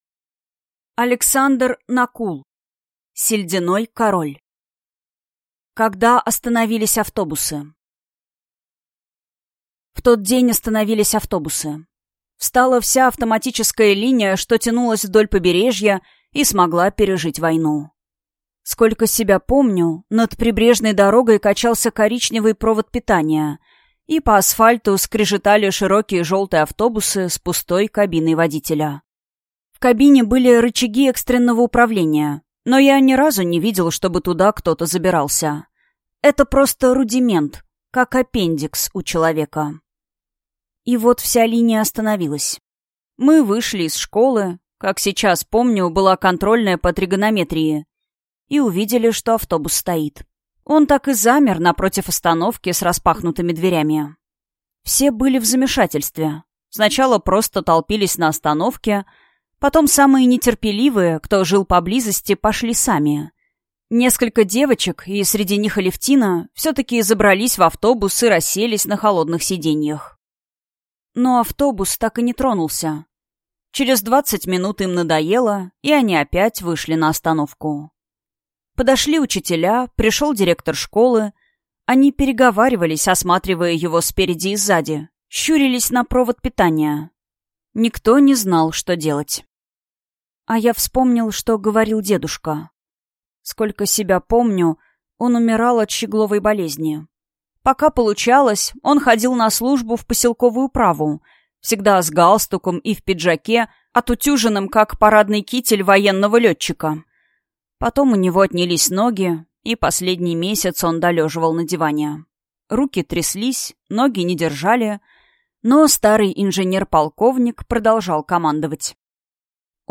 Аудиокнига Сельдяной Король | Библиотека аудиокниг